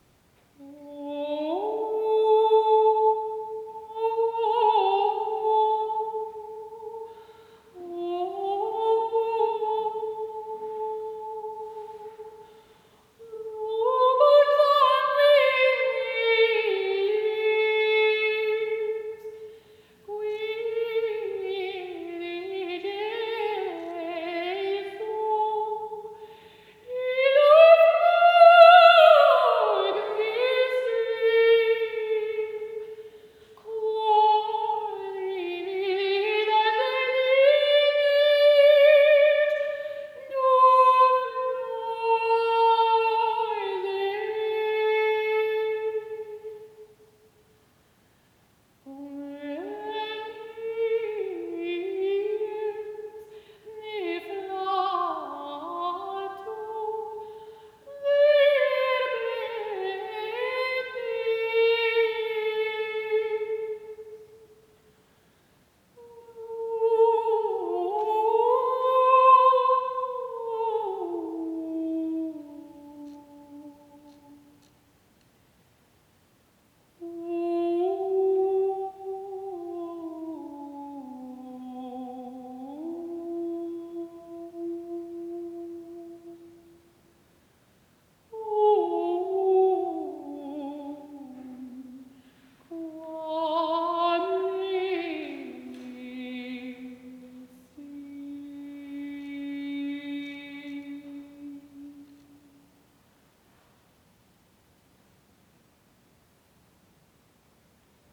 Two Healing Songs (from a live Meditation Concert)
a medieval healing chant